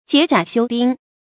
解甲休兵 jiě jiǎ xiū bīng
解甲休兵发音